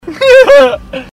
Laugh 15